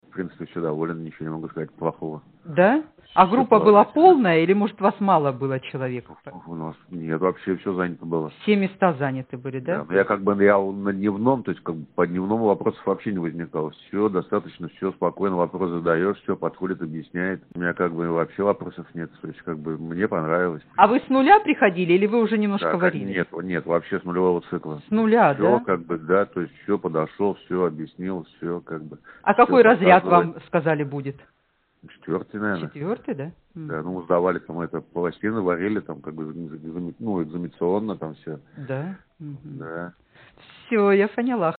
Аудио Отзывы